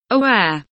aware kelimesinin anlamı, resimli anlatımı ve sesli okunuşu